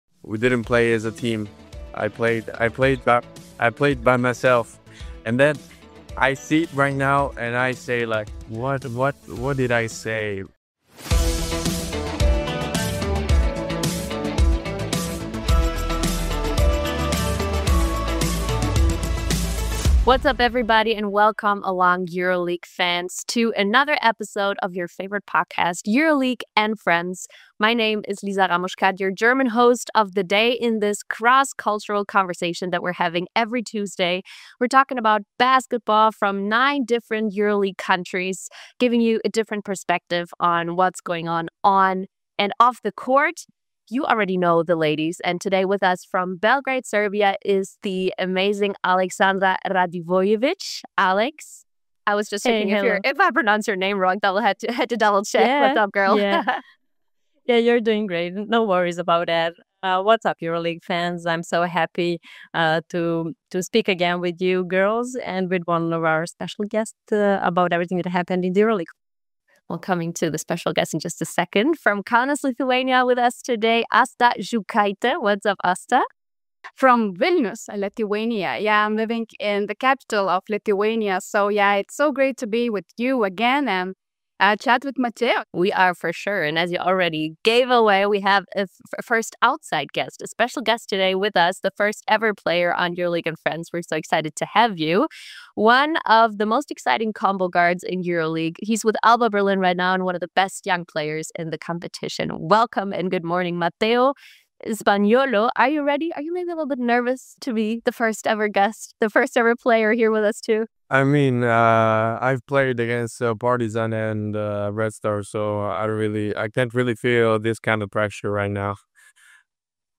In this episode of EuroLeague & Friends, we dive into the debate for November’s MVP, discussing standout performances and key contenders. Special guest Mateo Spagnolo shares his thoughts on Vassilis Spanoulis' transition to coaching and how his legacy continues to shape the EuroLeague.